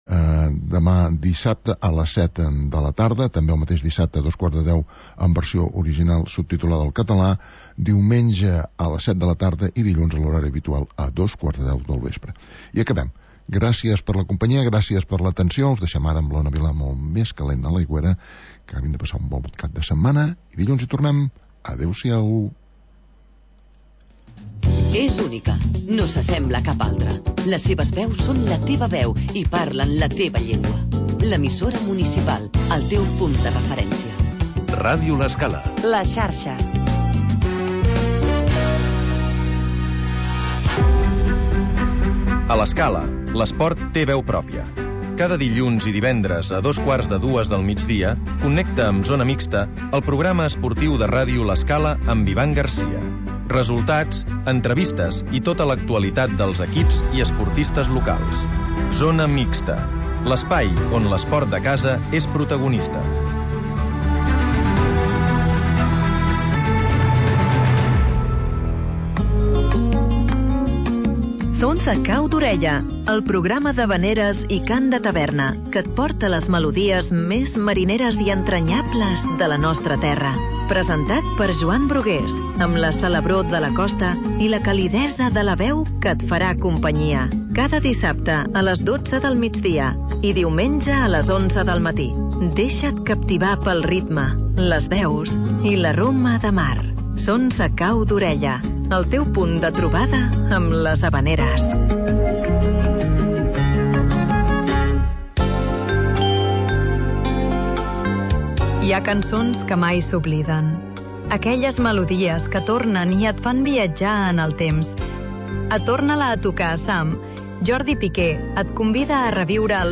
Magazín d'entreteniment per passar el migdia